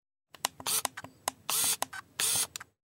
Звуки зума